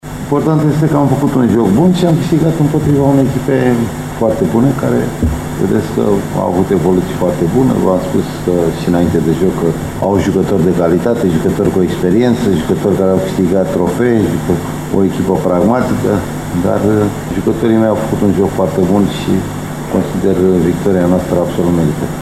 Antrenorul polist Florin Marin amintea, după meci, că elevii săi au ratat şi alte şanse de a înscrie, iar succesul aşteptat de etape bune este meritat: